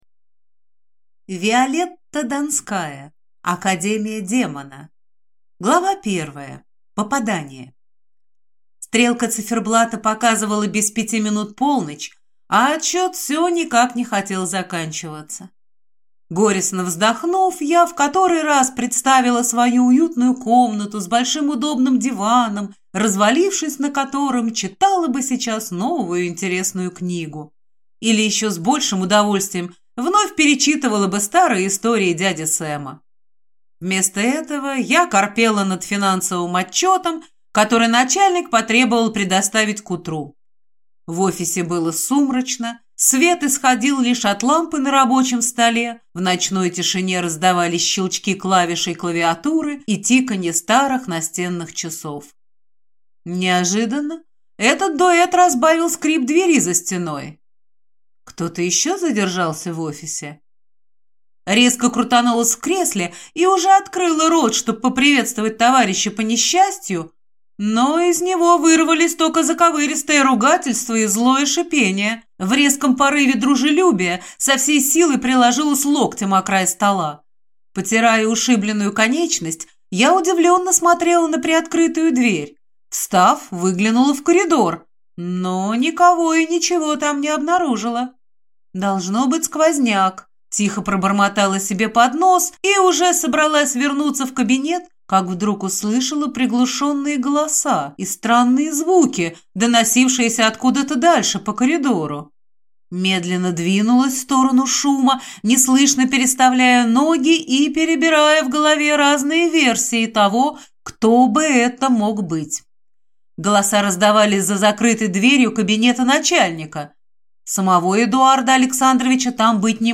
Аудиокнига Академия Демона | Библиотека аудиокниг